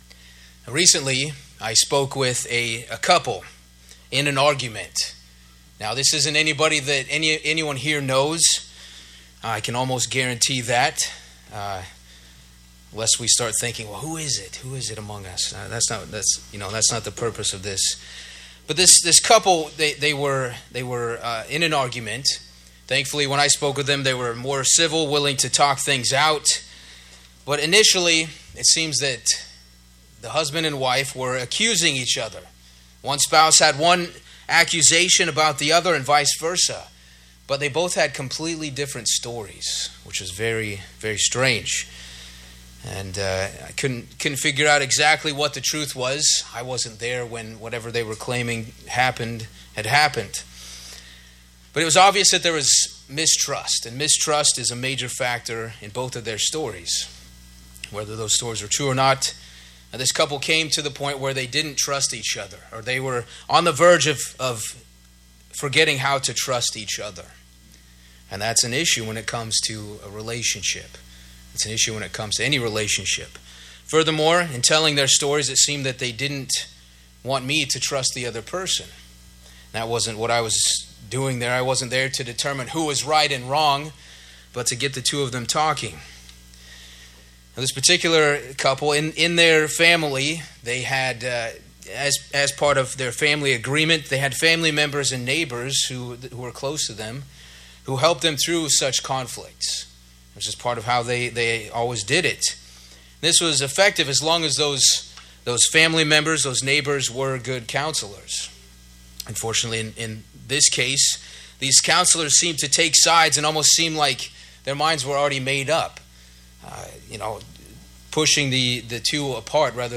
This sermon identifies three enemies of peace and three biblical principles that counter those enemies.